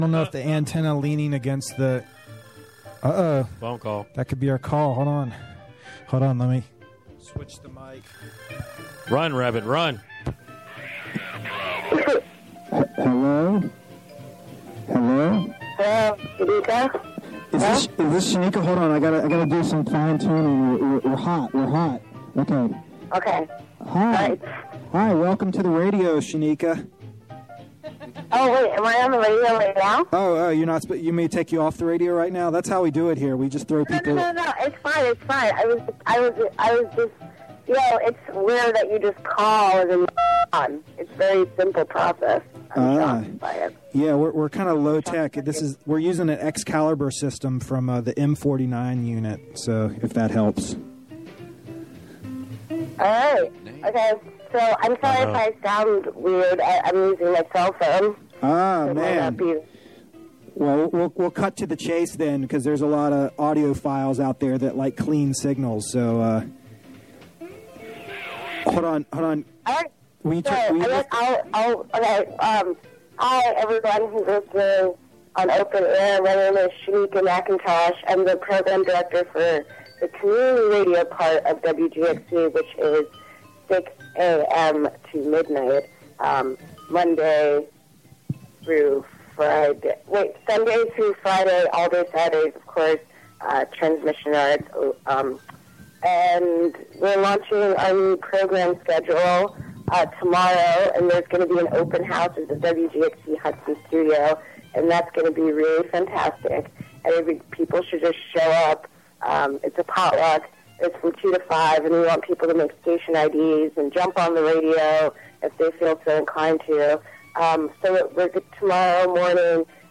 Interviewed
From "Open Air" from Catskill Community Center.